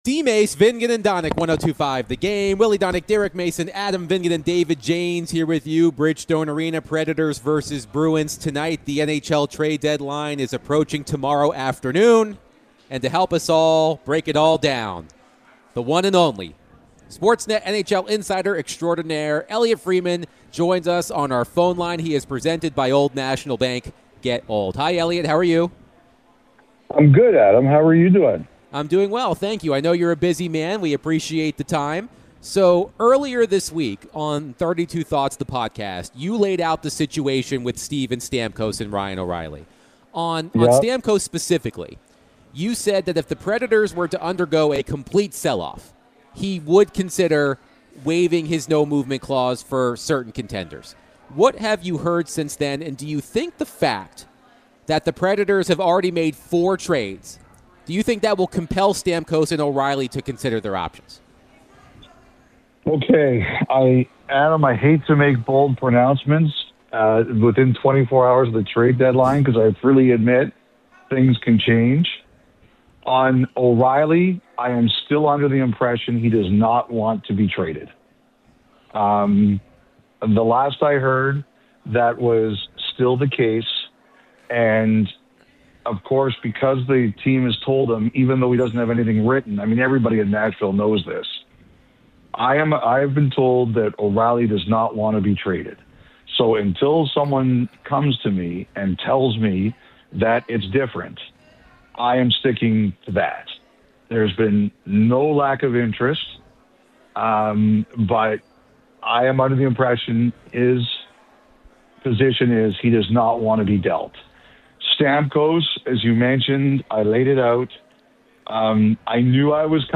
NHL Insider Elliotte Friedman joined DVD to discuss the Preds trade so far, NHL Trade deadline news, and more